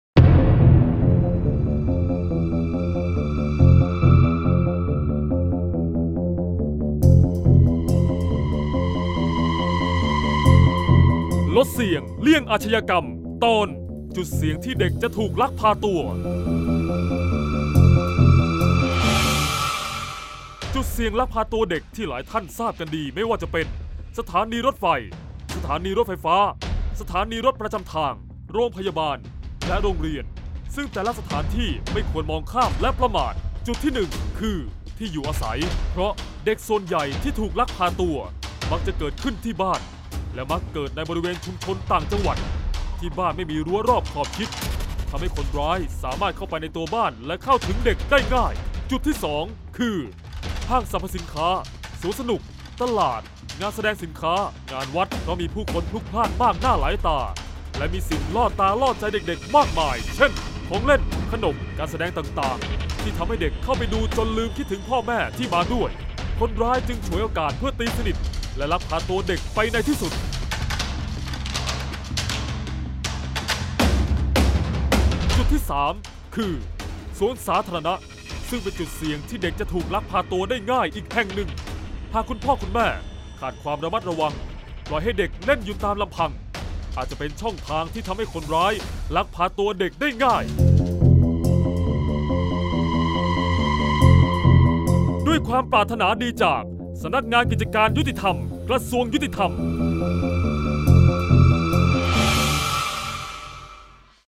เสียงบรรยาย ลดเสี่ยงเลี่ยงอาชญากรรม 16-จุดเสี่ยงลักพาตัวเด็ก